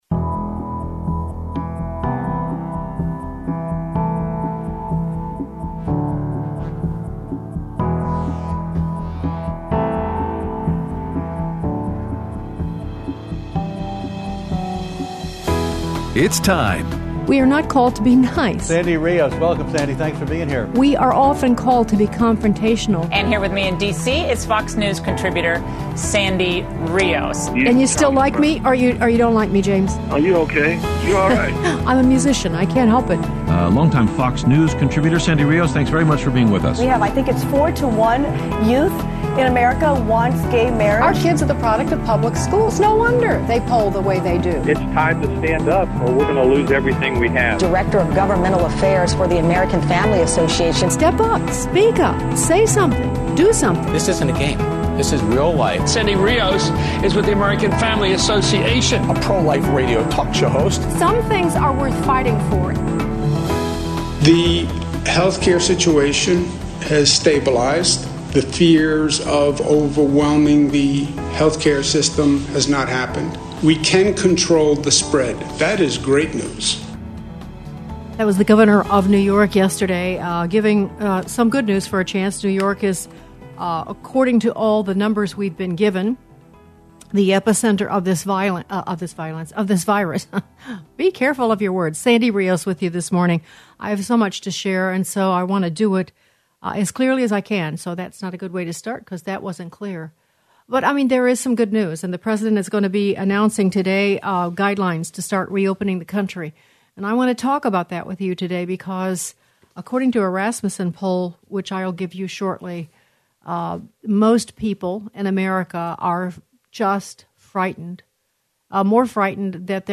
Death Rates For COVID-19 Include Suspected Positive In Counts, President Trump Threatens to Adjourn Congress To Get Recess Appointments, and Your Phone Calls